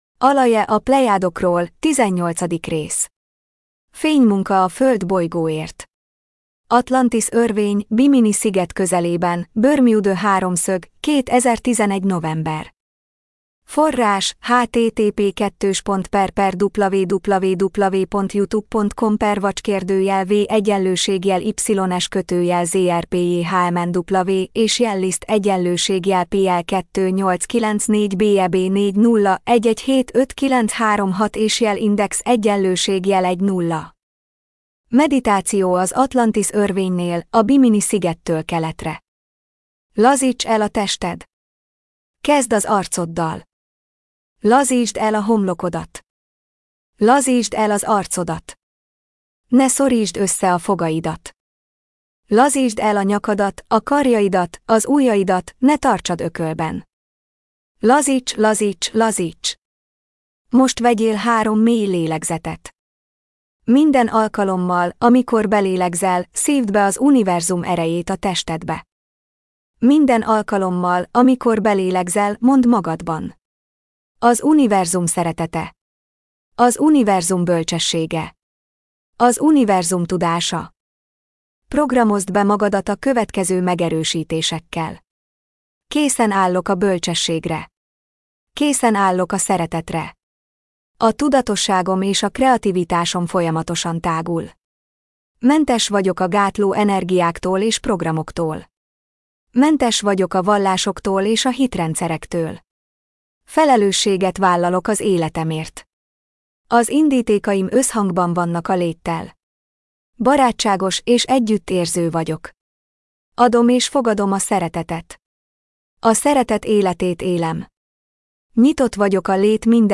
MP3 gépi felolvasás Magyar Alaje Honlap - Videók - 18. rész Alaje a Plejádokról - 18. rész Fénymunka a Föld bolygóért Atlantisz örvény, Bimini sziget közelében, Bermuda háromszög - 2011 november.